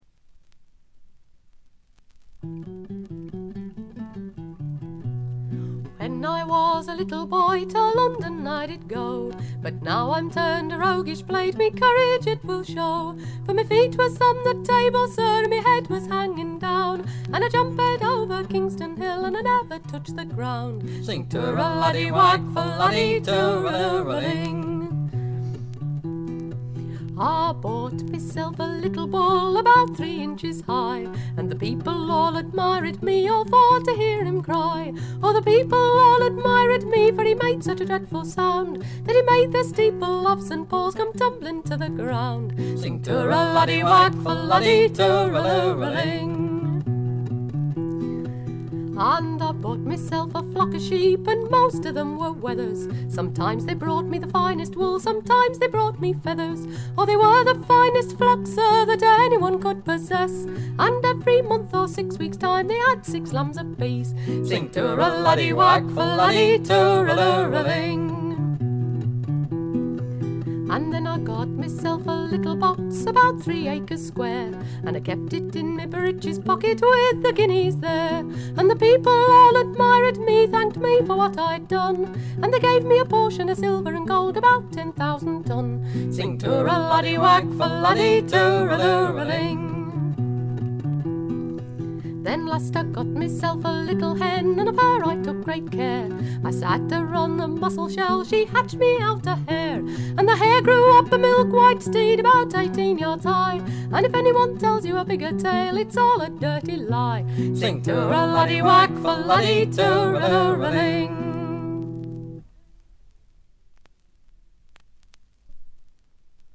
軽微なバックグラウンドノイズ、チリプチ。
試聴曲は現品からの取り込み音源です。